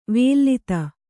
♪ vēllita